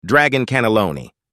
Category: Games Soundboard.